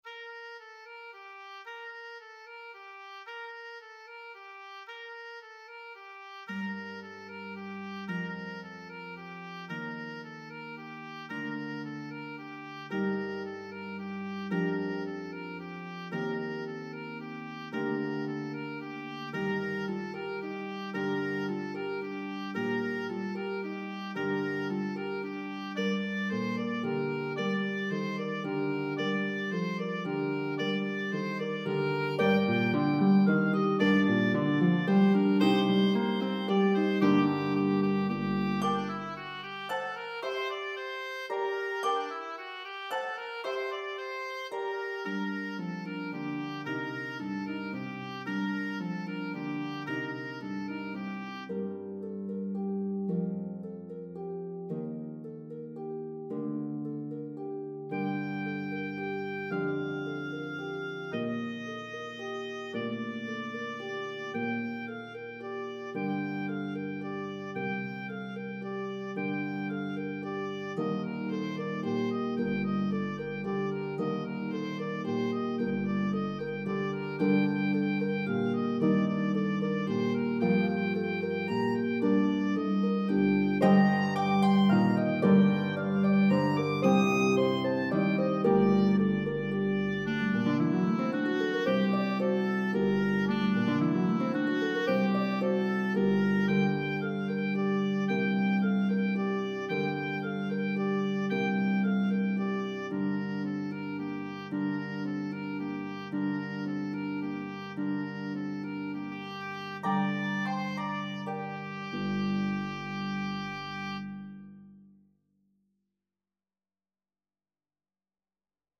Harp and Oboe version